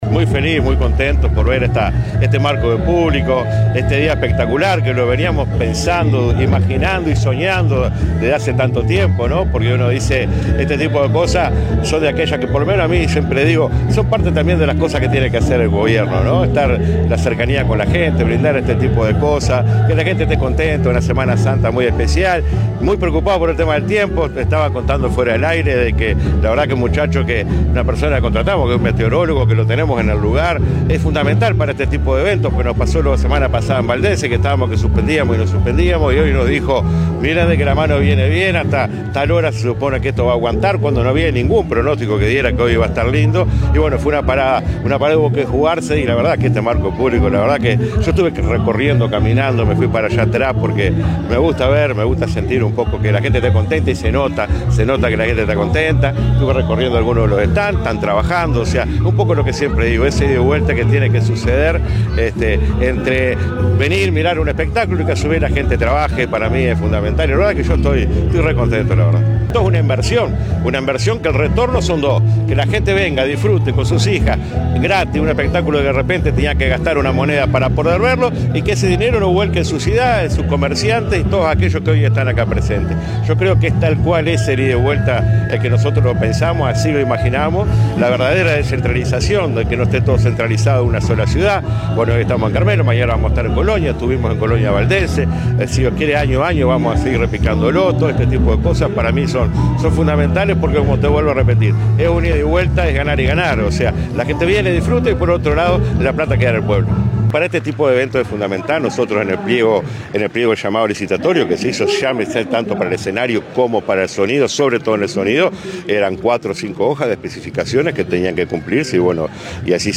El Intendente Guillermo Rodríguez dijo A Radio Carmelo que ante los pronósticos cambiantes se contrató a un asesor para que los ayude a tomar decisiones. También habló sobre la calidad del escenario y sonido montado en Carmelo. Dijo además que este tipo de espectáculos donde la gente disfruta y por otro lado se rebusca, se van a seguir desarrollando.